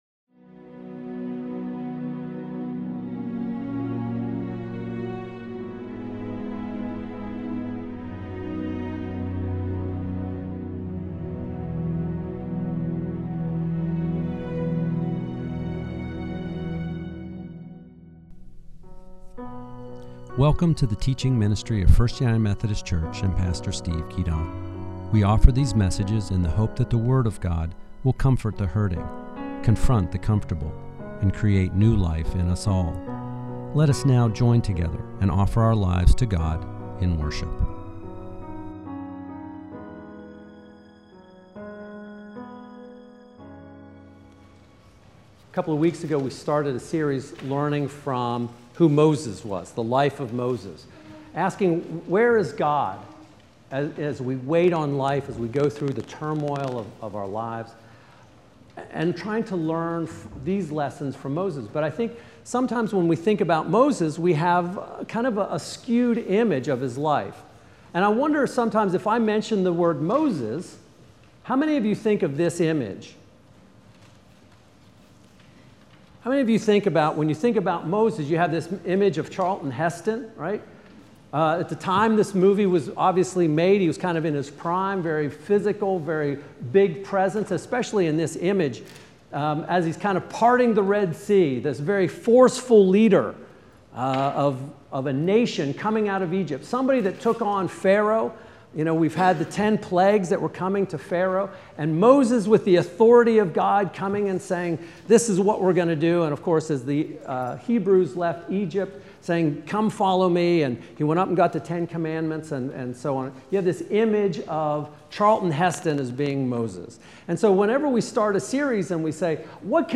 Moses the Wounded Deliverer, Late Service, August 24, 2014